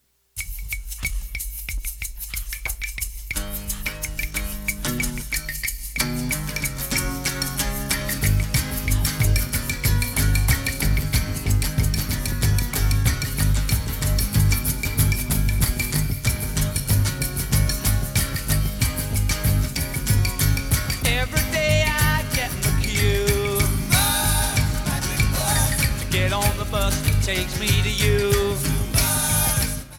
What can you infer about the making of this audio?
Not the best sound quality.